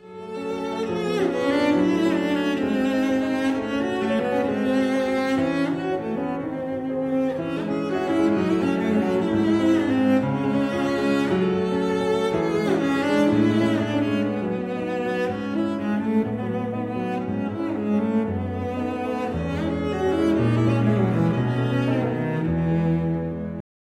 Violoncelle
Piano